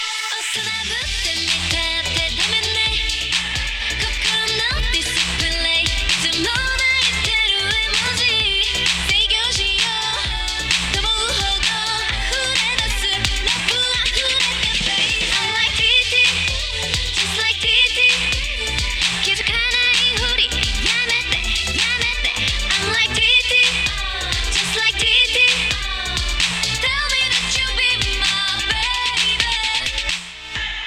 以下は最近までAndroidスマートフォン用に使っていたJBL Synchros E10のハイレゾ録音です。